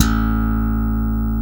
Index of /90_sSampleCDs/East Collexion - Bass S3000/Partition A/SLAP BASS-D